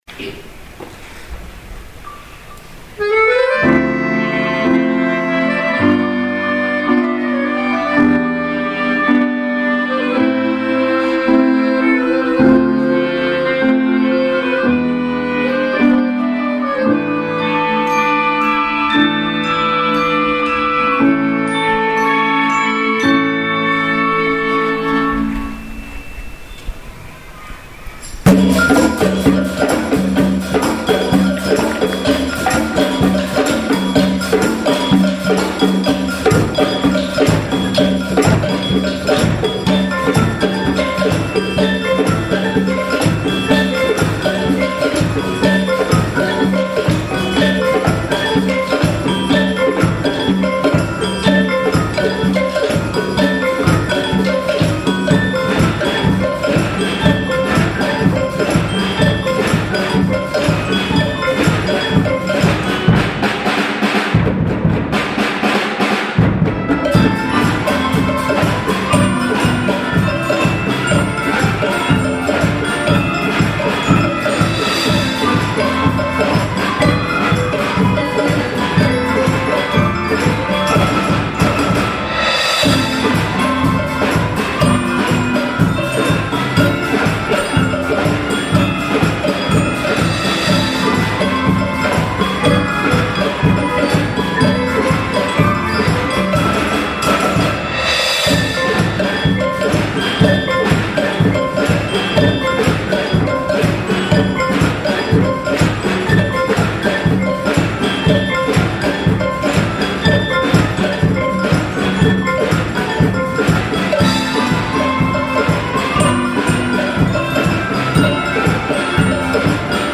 ５．６年生合同発表会
２月８日（水）に、高学年の合同発表会が行われました。
高学年の子どもたちの奏でる音色は、力強さと軽快さを織り交ぜた多彩な音色で、会場の聴衆も息を飲み、耳を傾けていました。